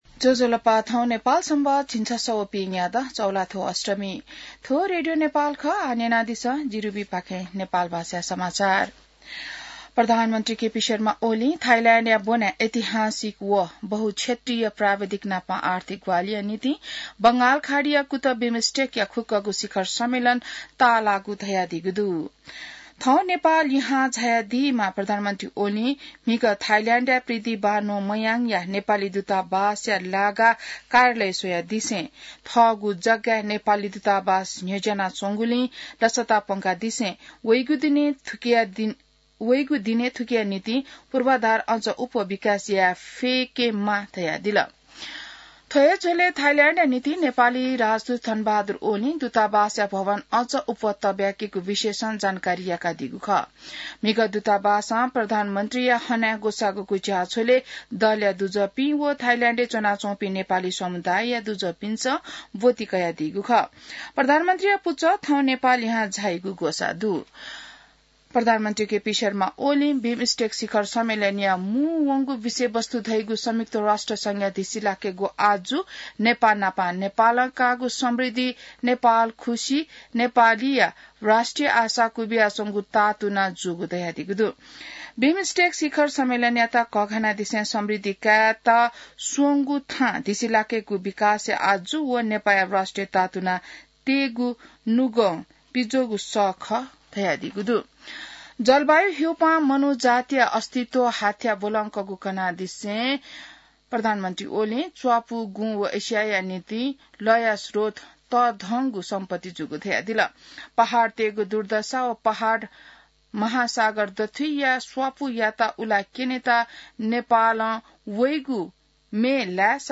An online outlet of Nepal's national radio broadcaster
नेपाल भाषामा समाचार : २३ चैत , २०८१